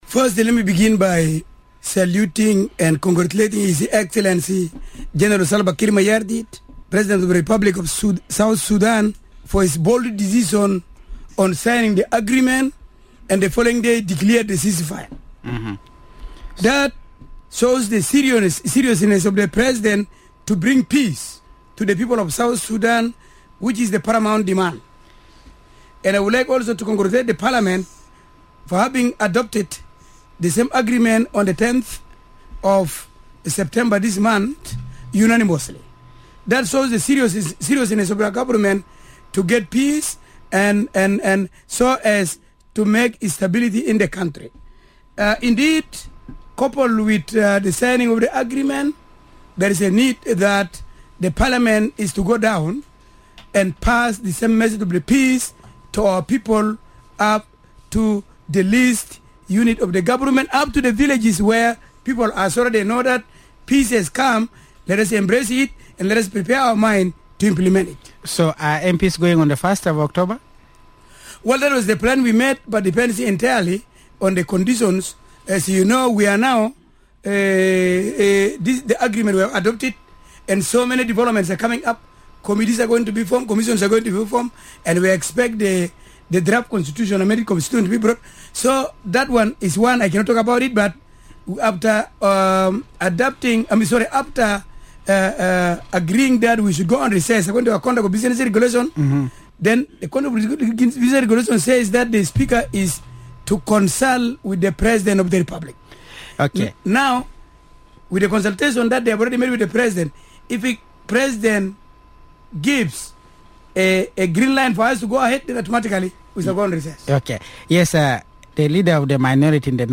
Chairperson of the Information Committee in Parliament, Thomas Wani Kundu and Leader of the minority in the house Onyoti Adigo spoke to Radio Miraya about the peace agreement and the need for public awareness.